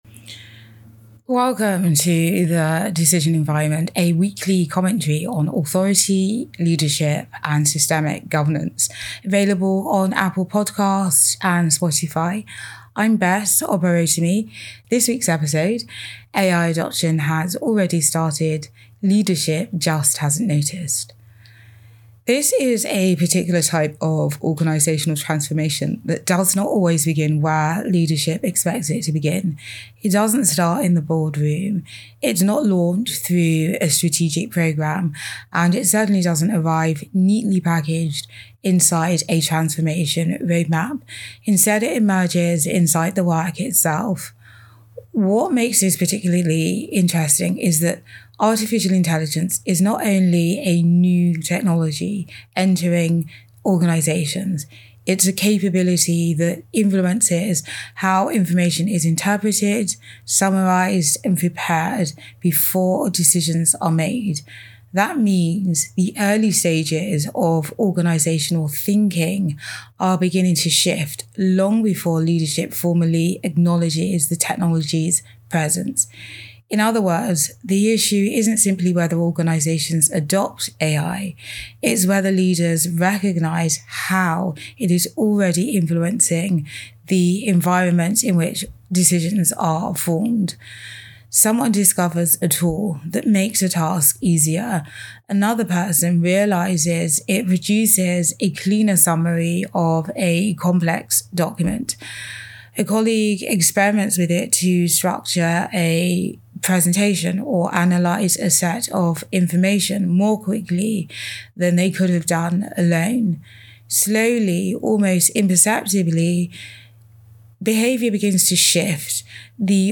ai-adoption-in-organisations-has-already-begun-audio-commentary.mp3